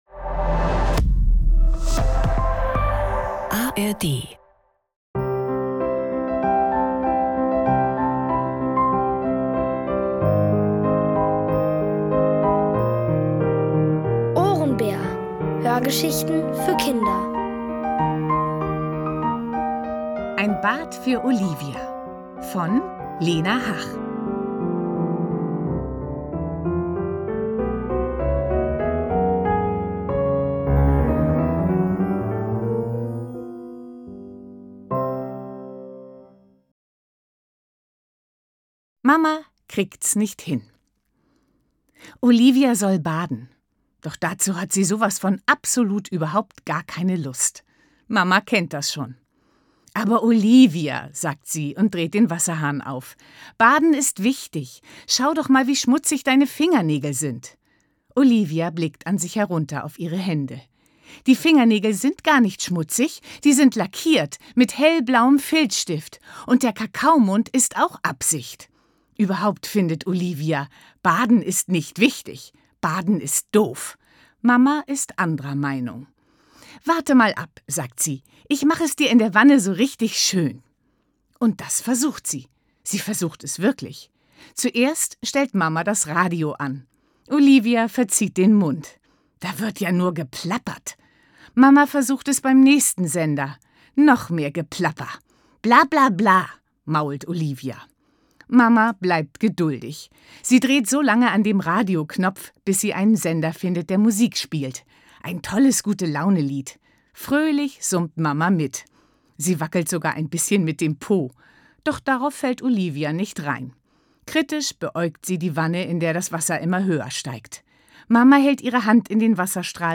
Ein Bad für Olivia | Die komplette Hörgeschichte! ~ Ohrenbär Podcast
Alle 3 Folgen der OHRENBÄR-Hörgeschichte: Ein Bad für Olivia von Lena Hach.